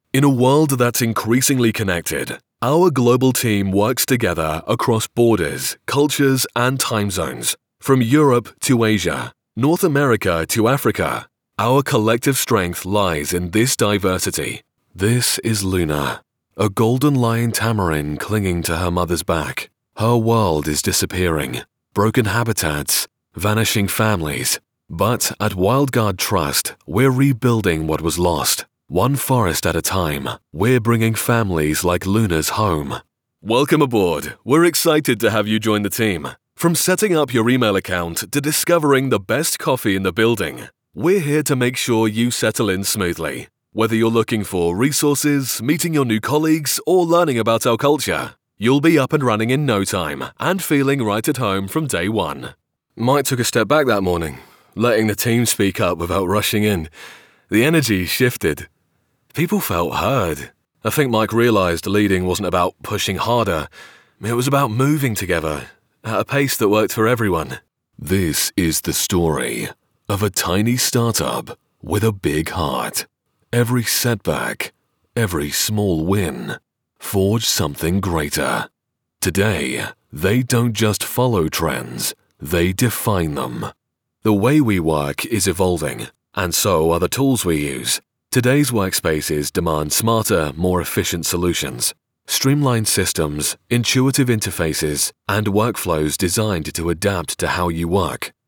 Anglais (Britannique)
Profonde, Cool, Polyvalente, Naturelle, Distinctive
Corporate